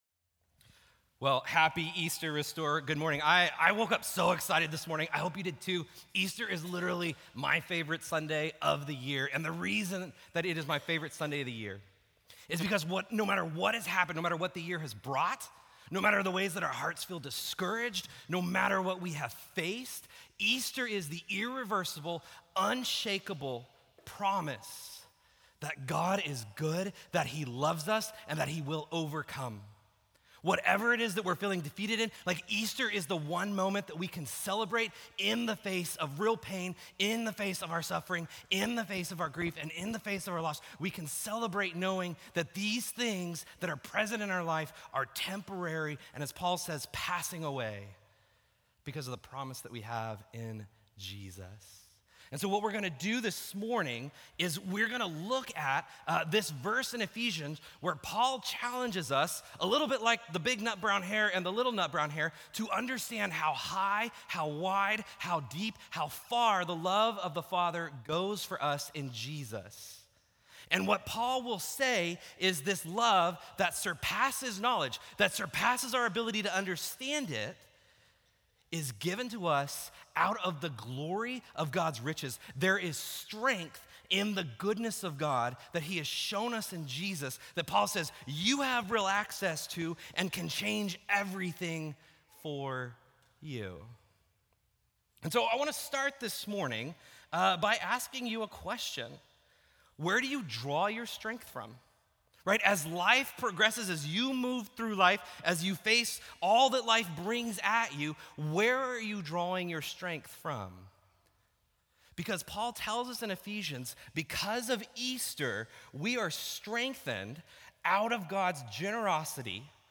Restore Houston Church Sermons Easter!